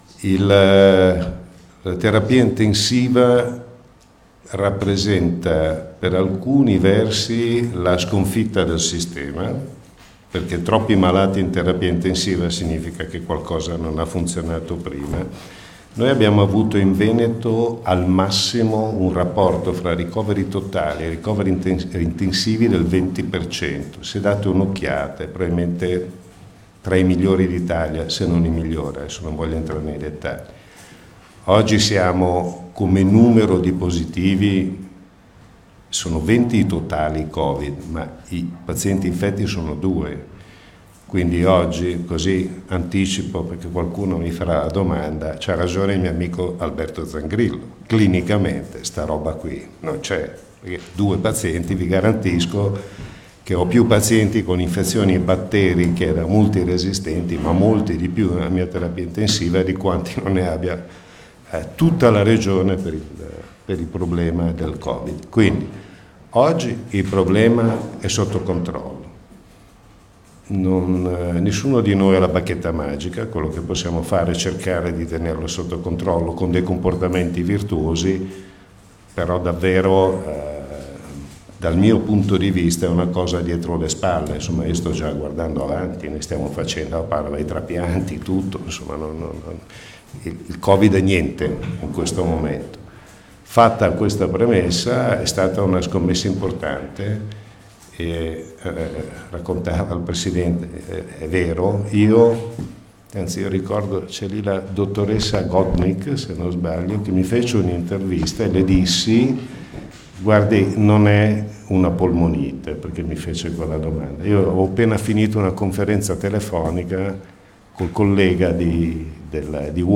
DURANTE LA CONFERENZA STAMPA DI ZAIA